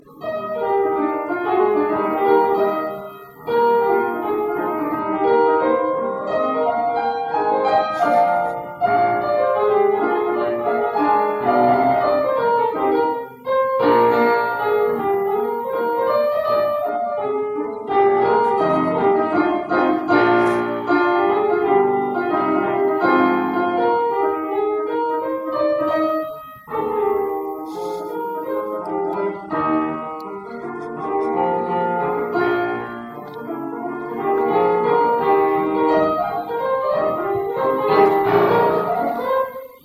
不用再问了，absolutely jazz。